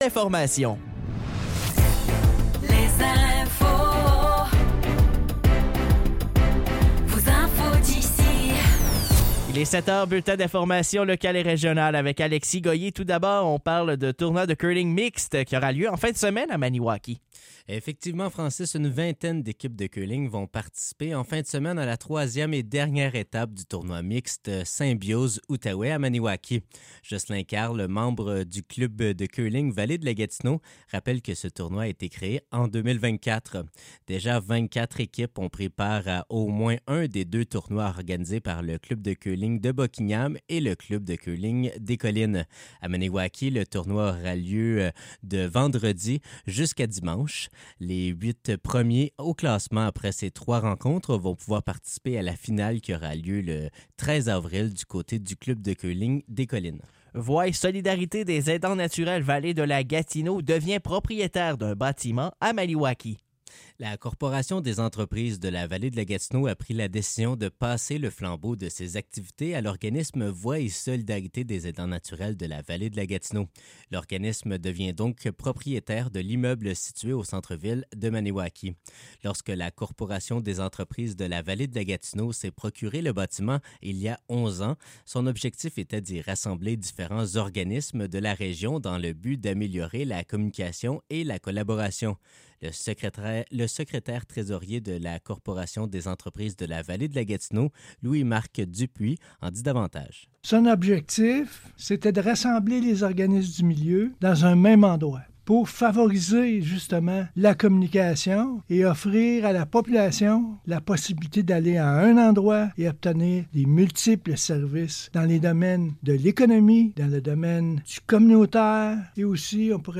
Nouvelles locales - 13 mars 2025 - 7 h